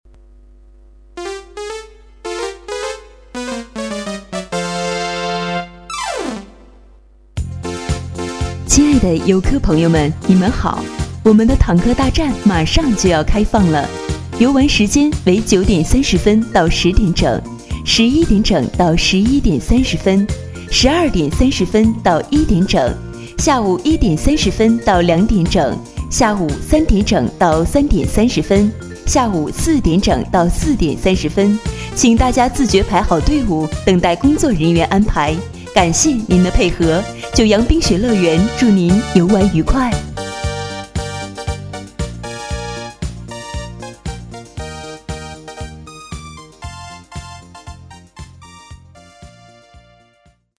移动水上乐园之九洋冰雪乐园广播
移动水上乐园之九洋冰雪乐园坦克大战广播词：